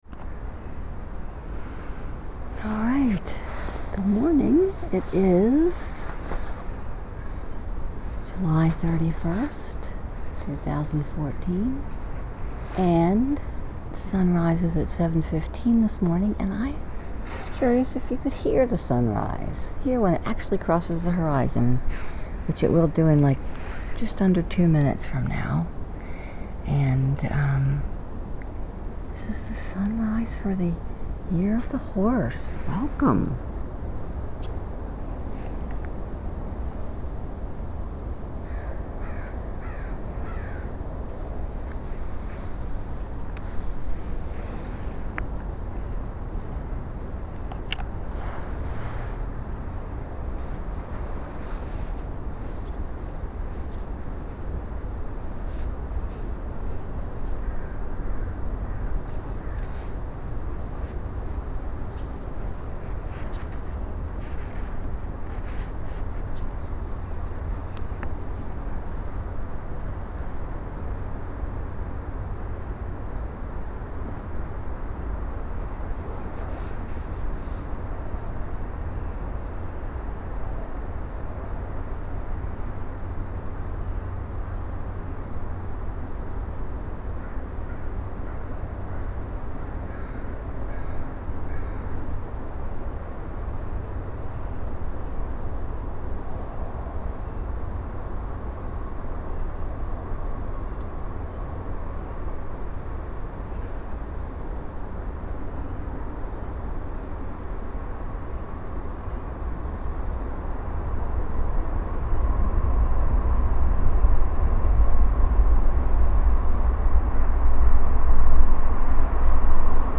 Sunrise 7:15 AM. San Francisco
(In this recording it happens during the passing of the streetcar at 7:15 AM)